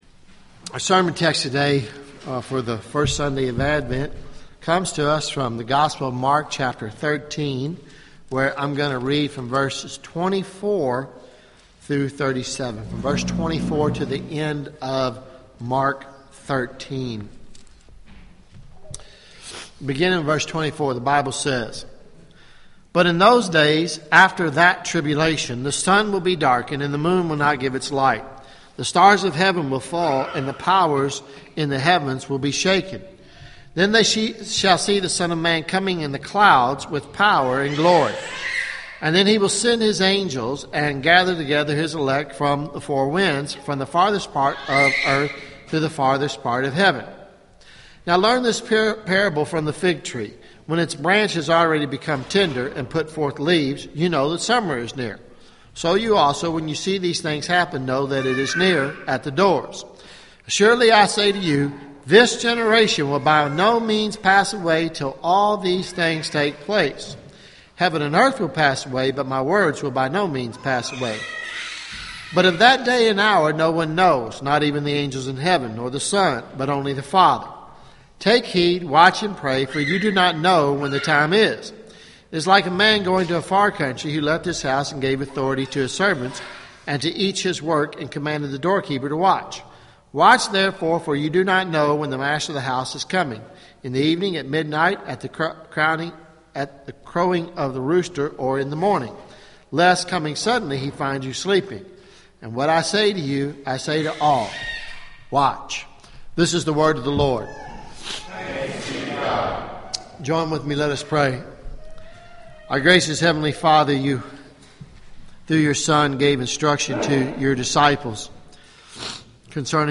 Sermons Nov 30 2014 “Be Alert!” preached on Nov. 30